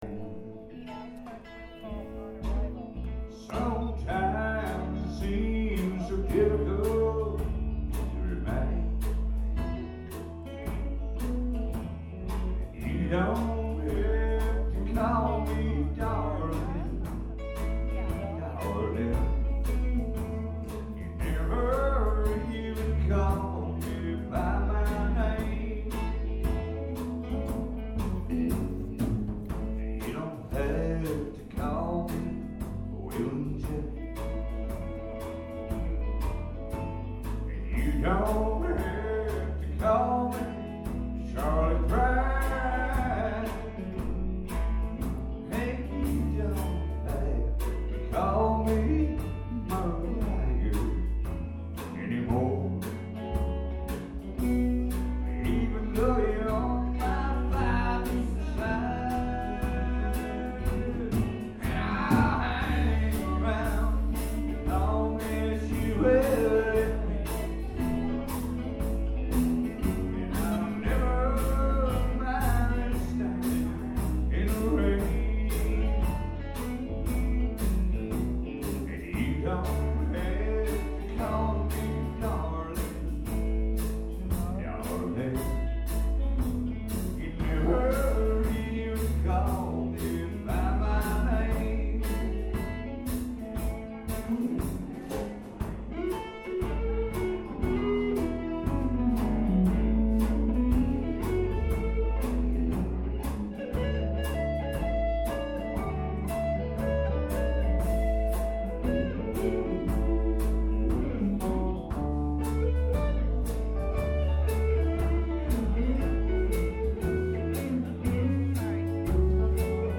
Bad Motor Scooter Band performs at Jollie's Lounge, April 11, 2014